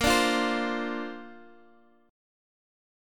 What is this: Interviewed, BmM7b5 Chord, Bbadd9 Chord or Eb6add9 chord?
Bbadd9 Chord